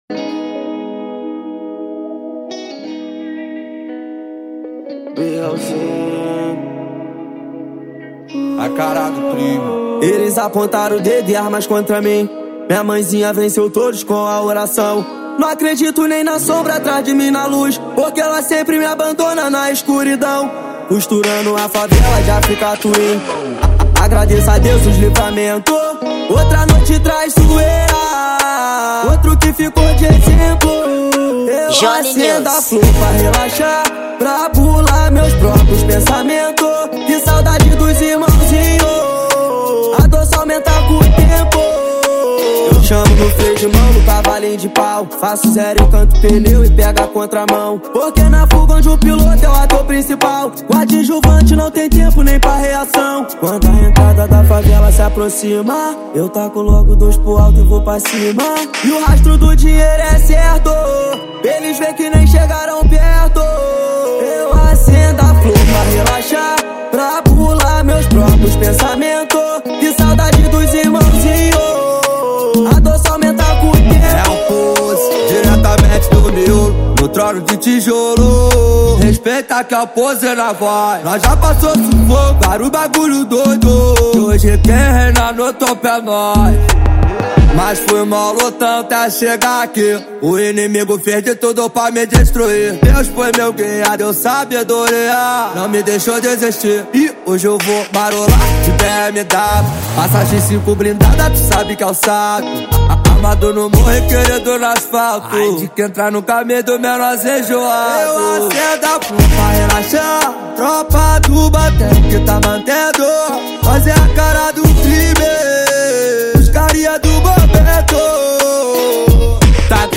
Gênero: Trap